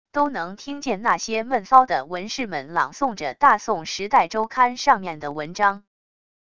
都能听见那些闷骚的文士们朗诵着大宋时代周刊上面的文章wav音频生成系统WAV Audio Player